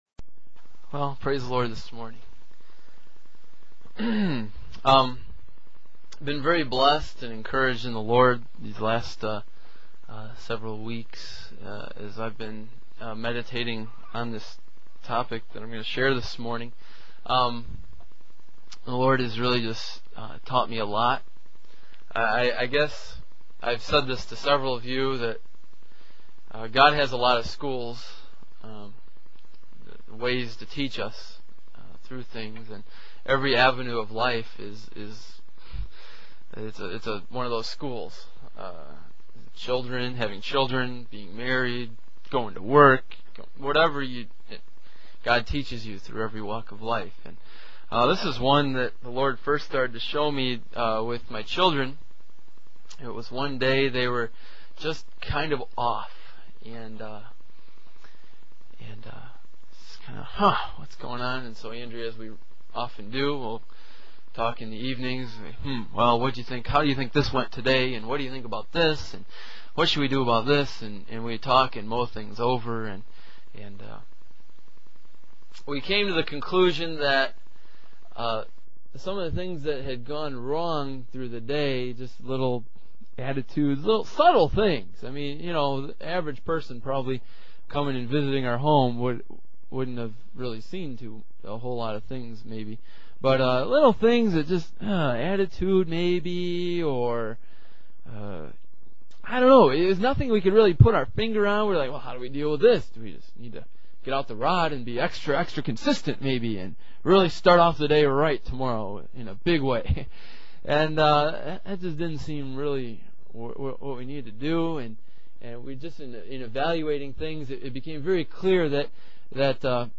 In this sermon, the speaker reflects on their personal experiences with their children and how it relates to our relationship with God.